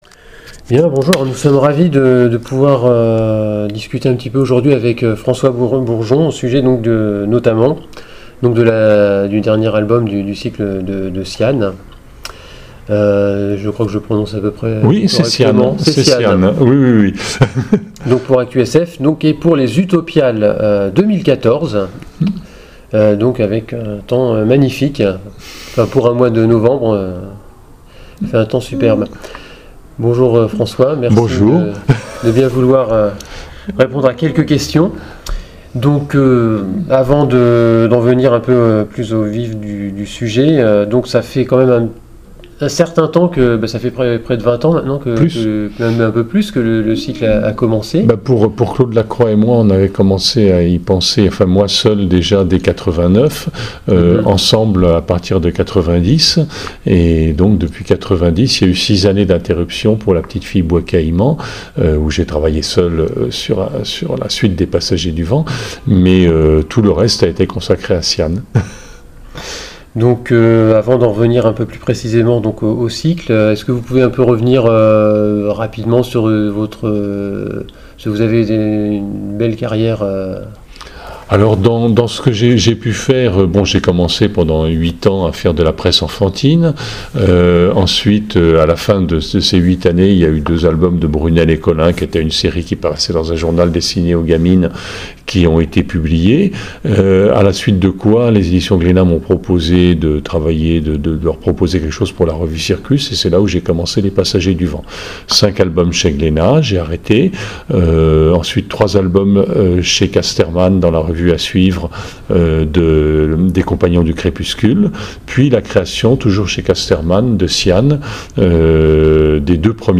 ITW François Bourgeon (Utopiales)